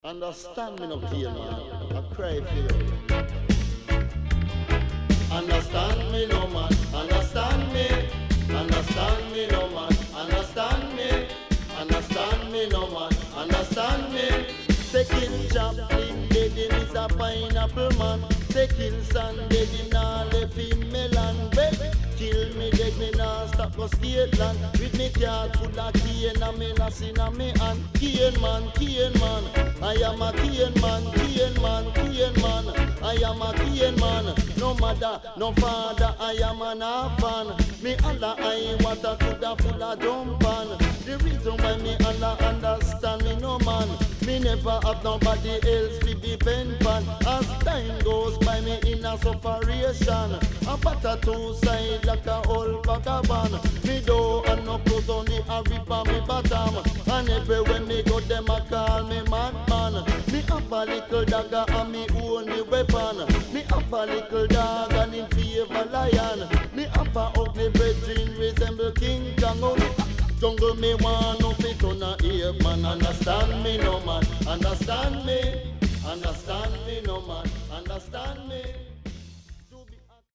Nice Deejay